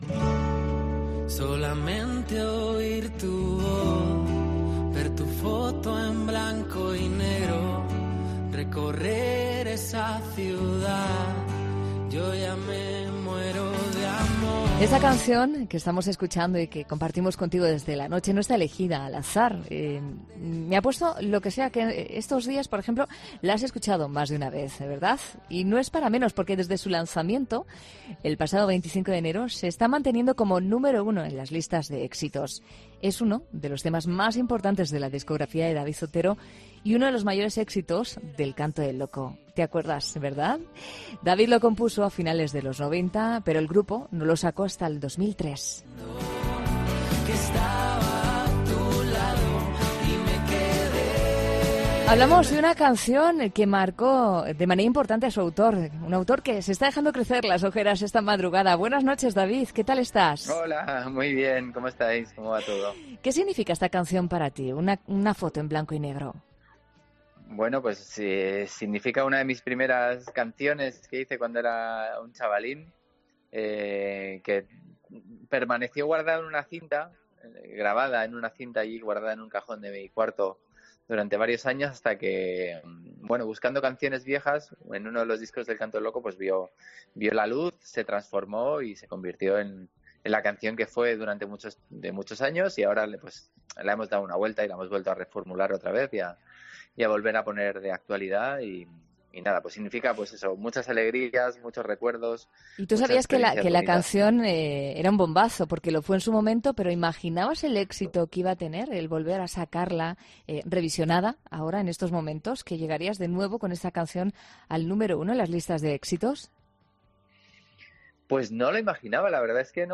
El cantante madrileño habla en 'La Noche' sobre las canciones que ha revisionado este 2020